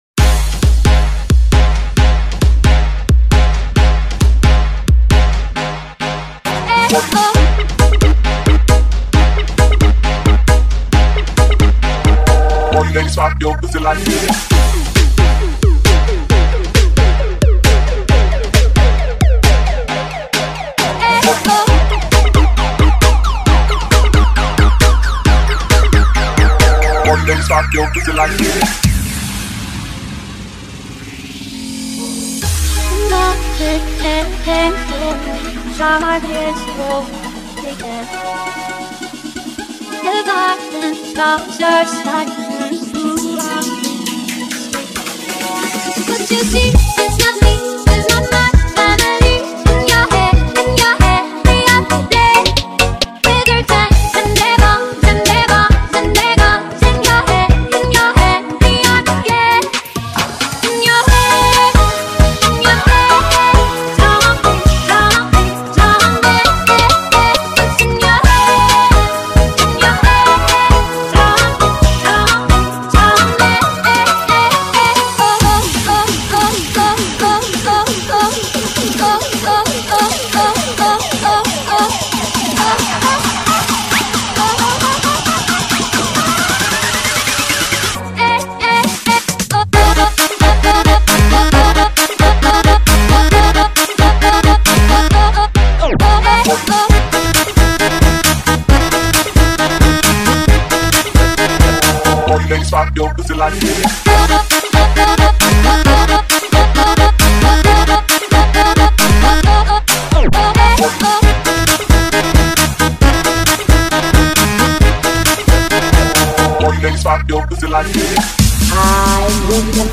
Full Bass yang sangat super yang memgisi lagu terbaru ini.
Barat Dj